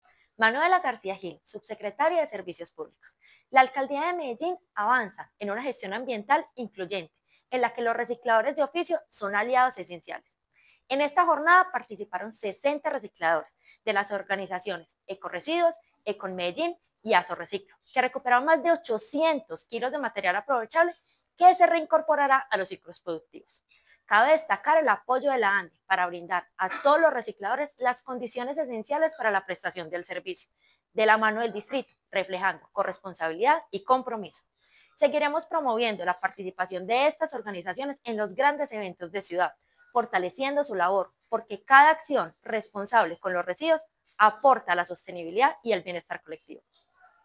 Declaraciones subsecretaria de Servicios Públicos, Manuela García
Declaraciones-subsecretaria-de-Servicios-Publicos-Manuela-Garcia.mp3